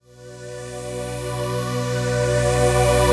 VEC3 FX Athmosphere 21.wav